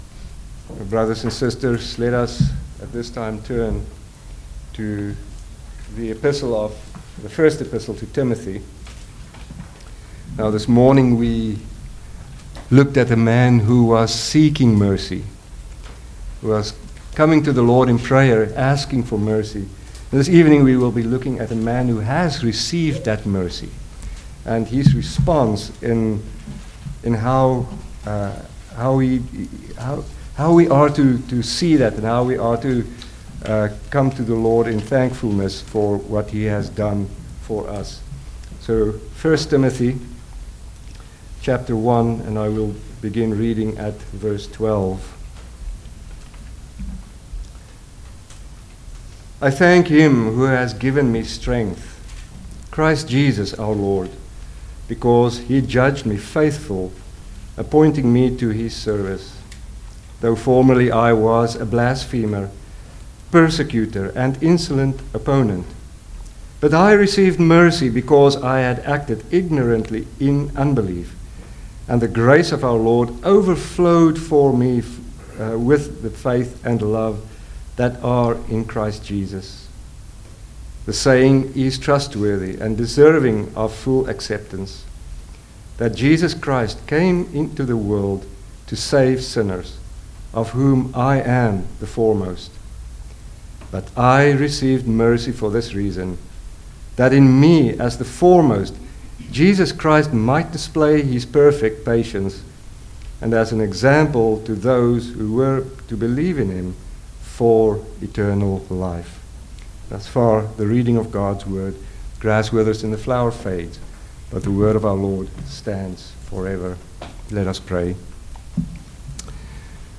Guest Preachers Passage: 1 Timothy 1:12-16 Service Type: Sunday Evening Service Download the order of worship here .